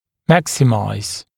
[‘mæksɪmaɪz][‘мэксимайз]увеличивать максимально